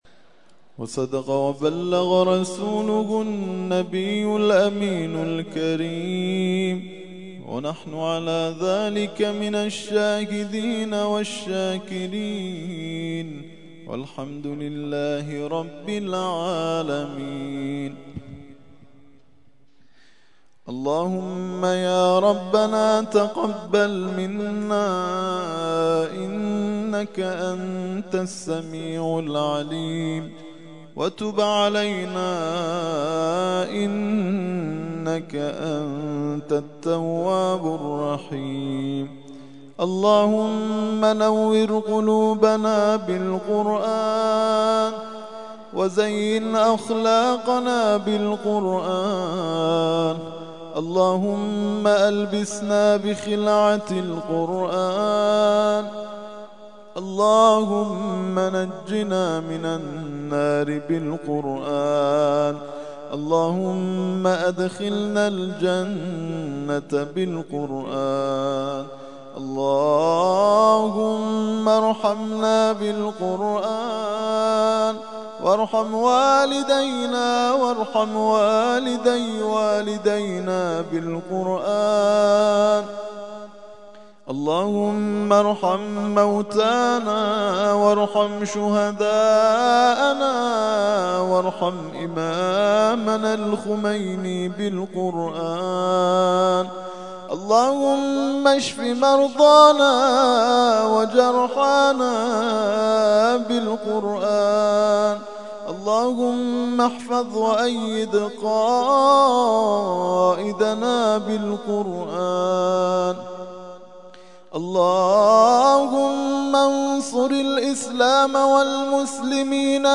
ترتیل خوانی جزء 24 قرآن کریم در سال 1391
دعای ختم قرآن - پایان جزء ۲۴